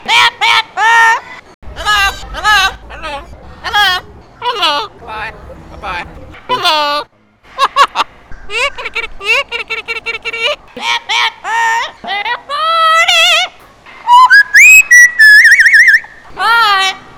This .wav file is from several Parrots at Jungle Island - Miami.
Parrot_Ringtone_Bad_Bad_Bird.wav